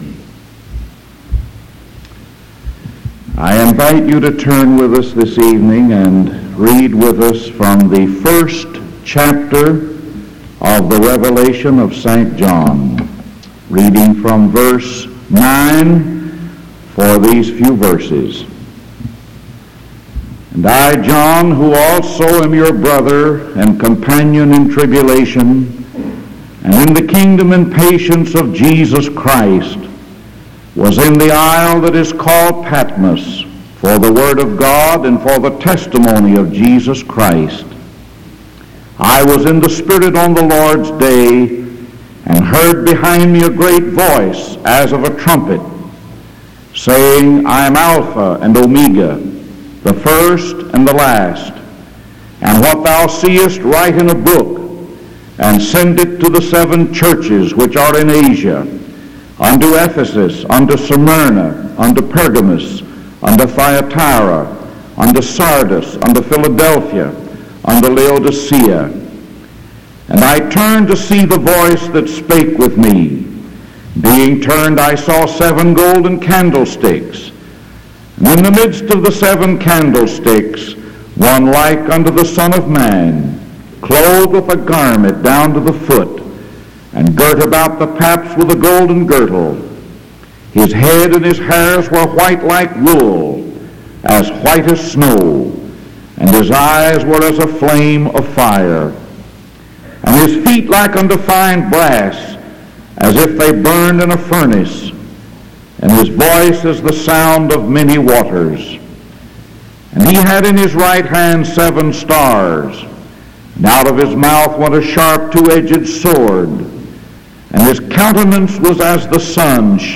Sermon August 5 1973 PM